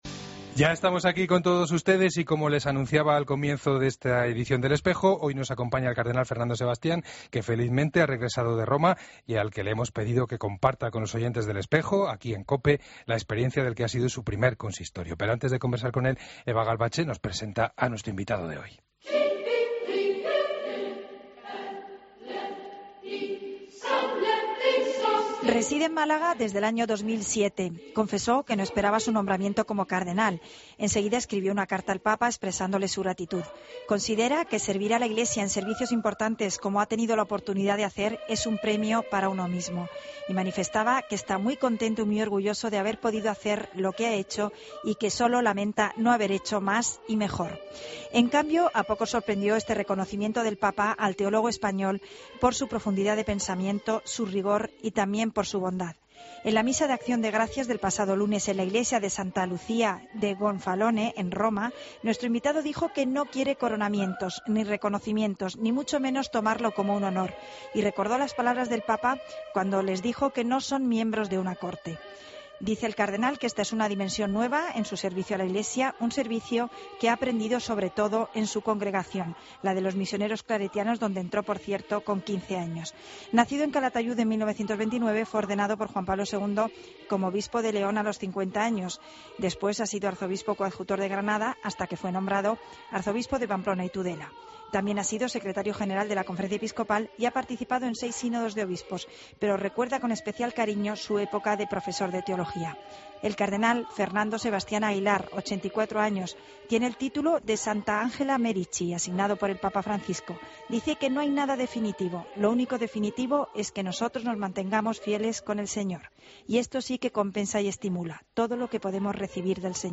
AUDIO: Escucha la entrevista completa al cardenal Fernando Sebastián en 'El Espejo' de COPE